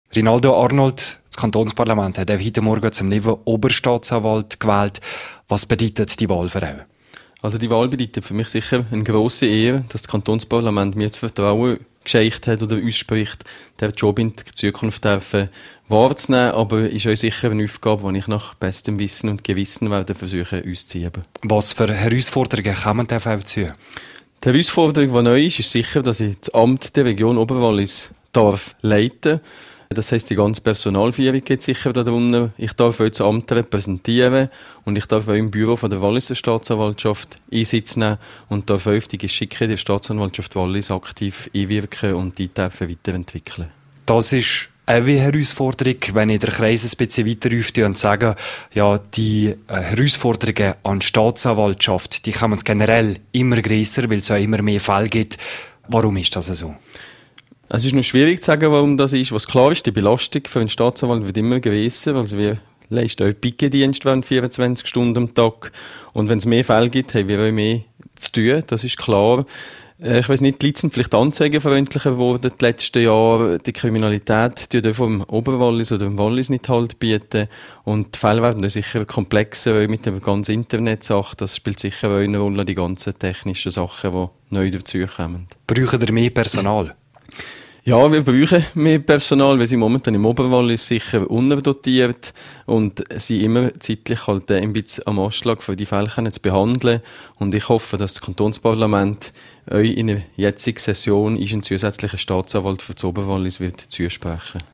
Zum neuen Generalstaatsanwalt wurde Nicolas Dubuis gewählt./kb Interview mit Rinaldo Arnold (Quelle: rro)